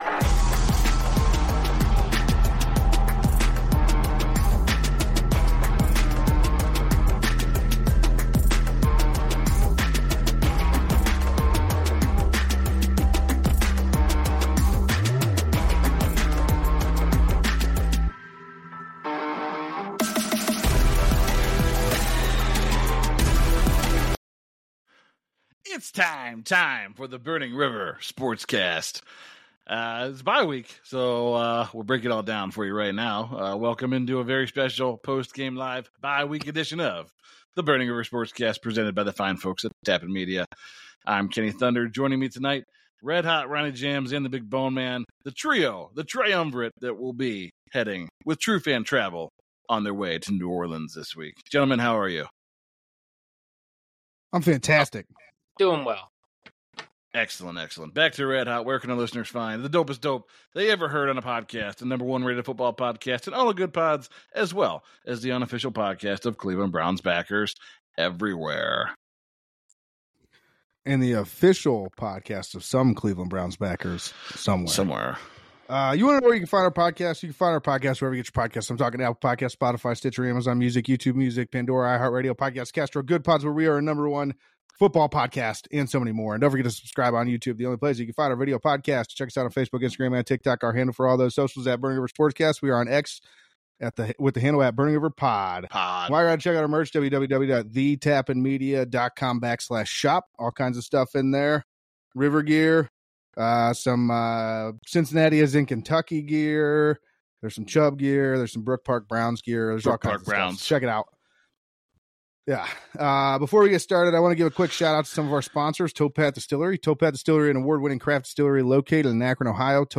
Time for Burning River Sportscast to go live again! Join us as we discuss the BYE week moves for the Browns, the first half of the Cleveland Browns season, and what we can expect the rest of this season and beyond!